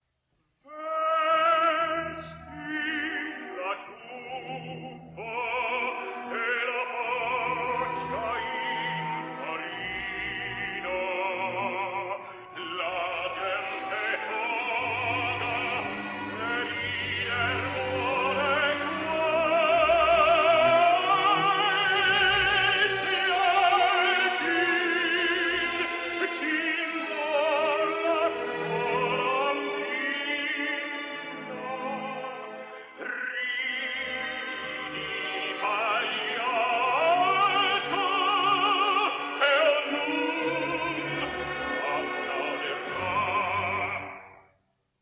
Canadian Tenor.
And this week, I’ve chosen to talk about one of the great individualists of international opera, the Canadian dramatic tenor, Jon Vickers.